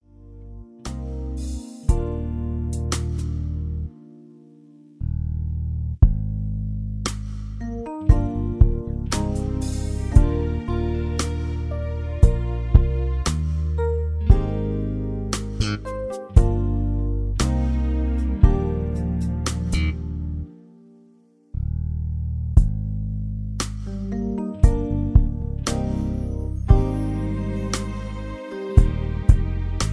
(Key-Eb) Karaoke Mp3 Backing Tracks